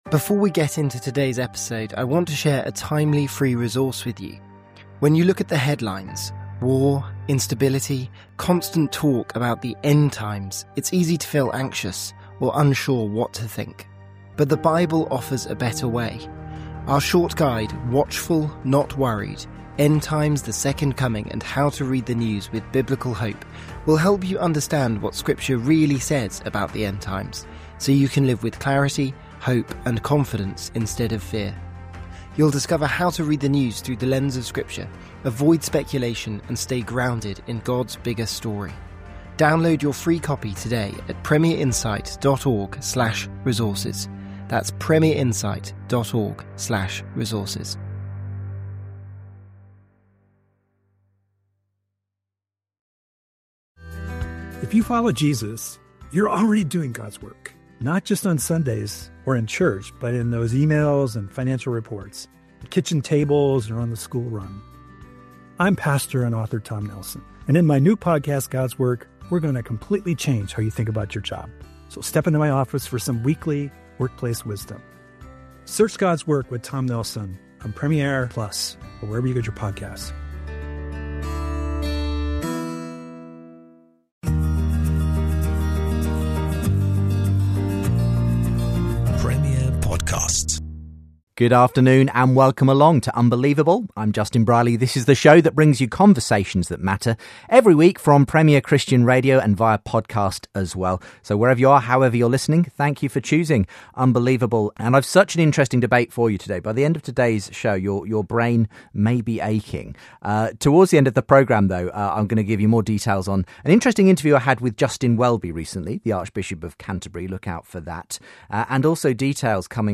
US theologian/philosopher David Bentley Hart author of ‘The Experience of God: Being, Consciousness, Bliss’ joins the conversation to debate whether God is the ground of consciousness and our sense-making abilities.